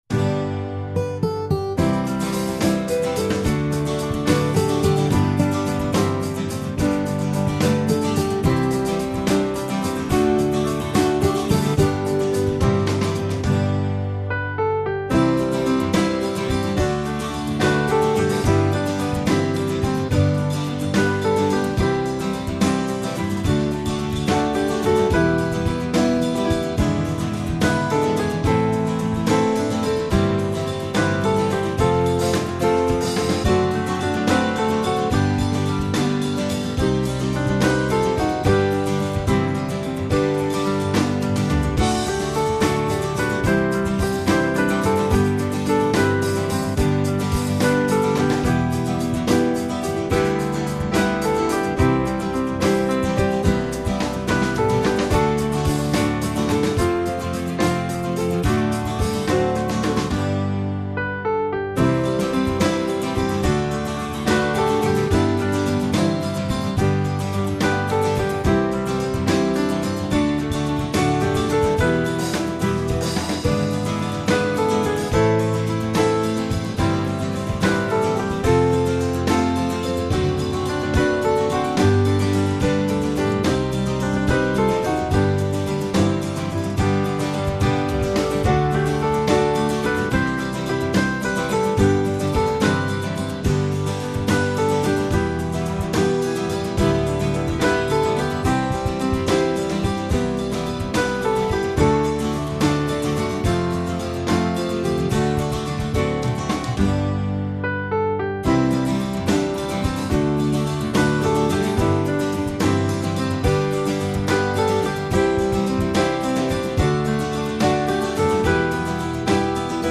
I didn’t add the harmony line to my backing.